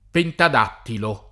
pentad#ttilo] agg.